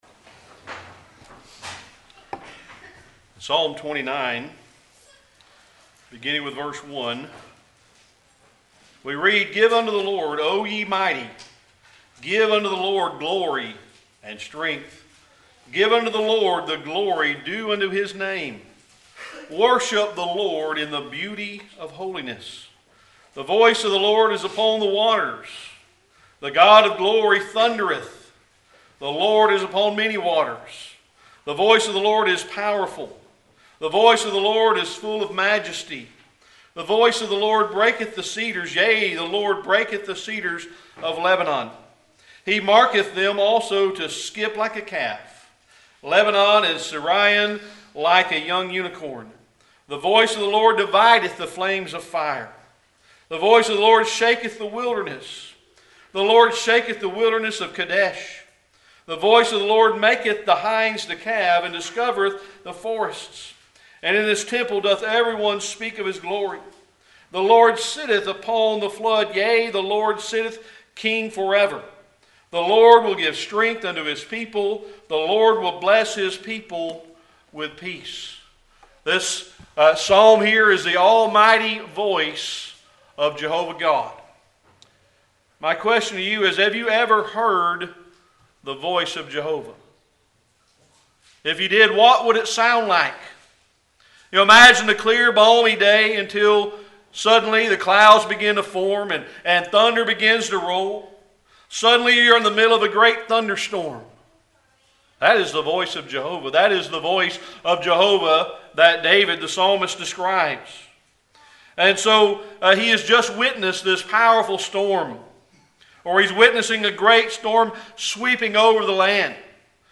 Series: Sermon Archives
Psalm 29 Service Type: Sunday Evening Worship Psalm 29 is called the Psalm of the seven thunders.